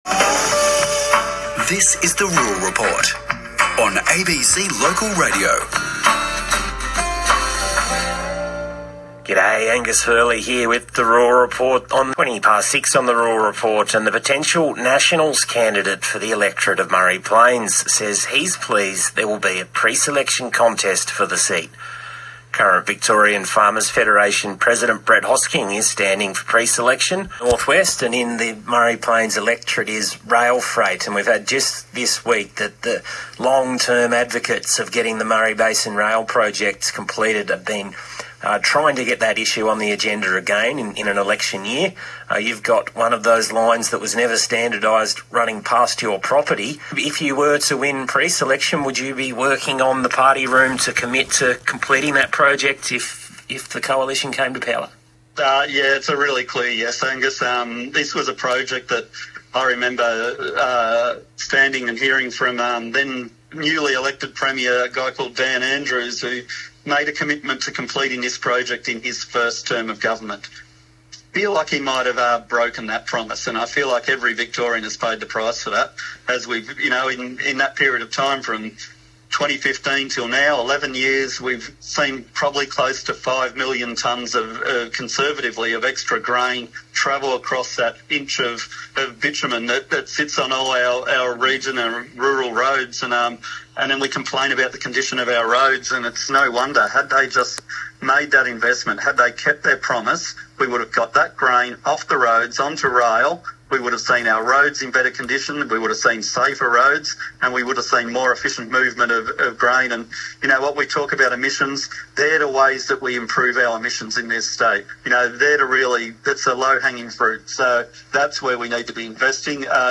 Extracts from two interviews with the ABC Radio in April '26 including Ouyen Inc & VFF